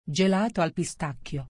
pronunciation-gelato-al-pistacchio.mp3